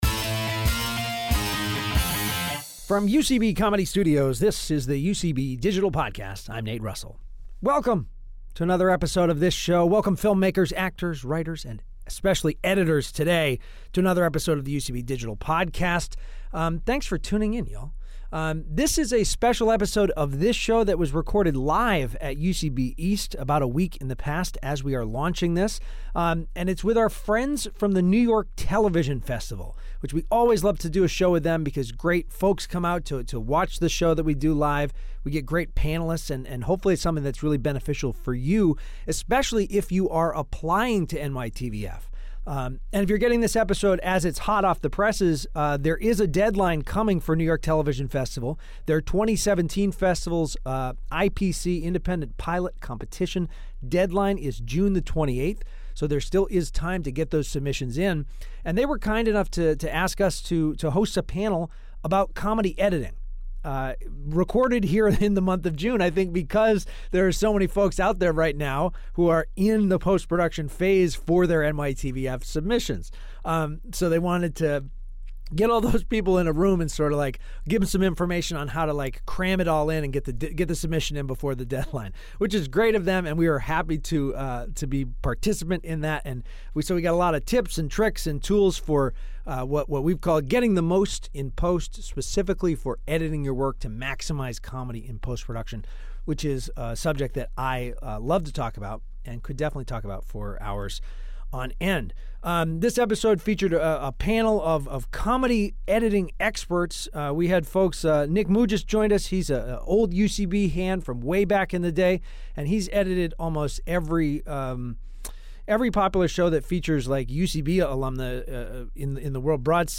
This week UCB Digital Podcast has teamed up with the New York Television Festival to bring you a panel discussion about the comedy editing process with some of the city's best editors and visual effects artists.